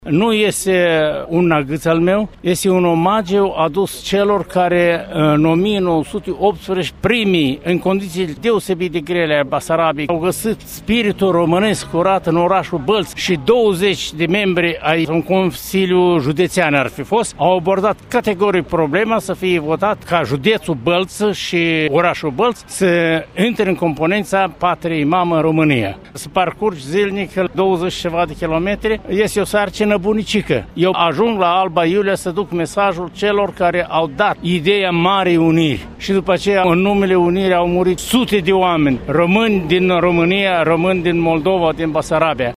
Într-un interviu acordat Radio Iași, Ion Mărgineanu a vorbit despre gestul său și despre motivul alegerii orașului Bălți ca punct de plecare al călătoriei sale.